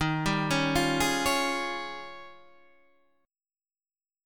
D# 11th